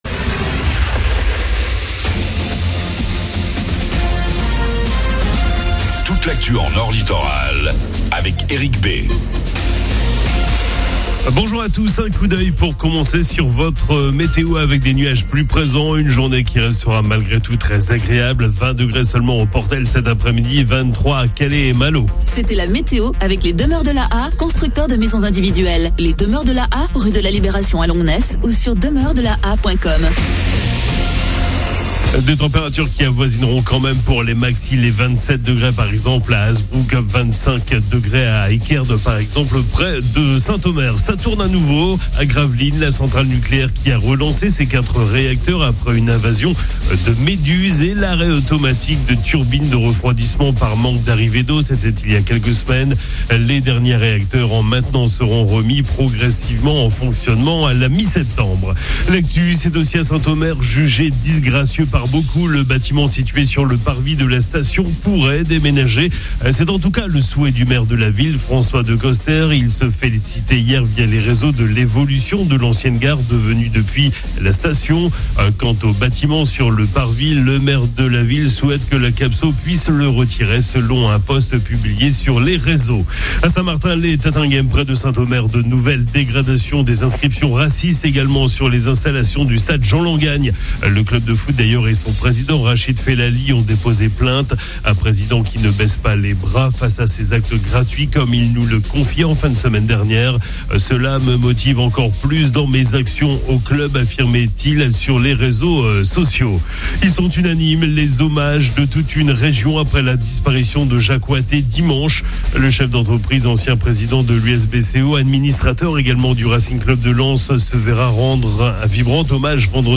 FLASH 26 AOUT 25